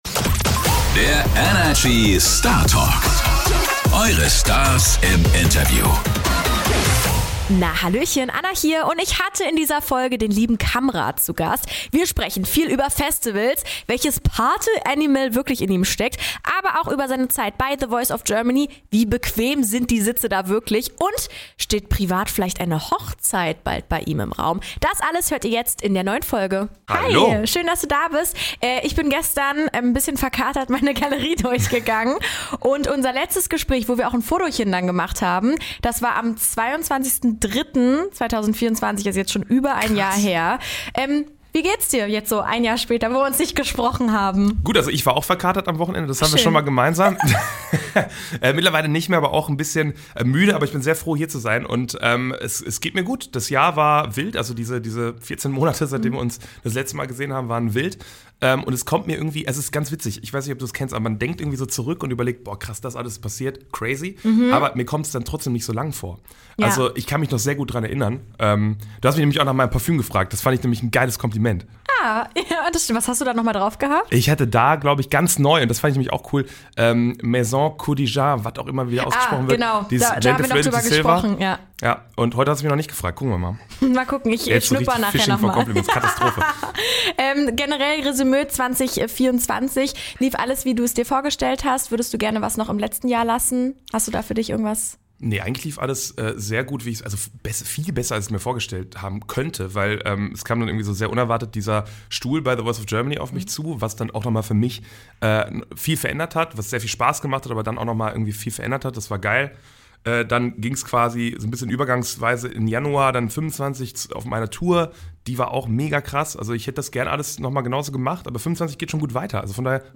In dieser Startalk Folge ist Sunny-Boy Kamrad genauso offen und ehrlich, wie wir ihn kennen!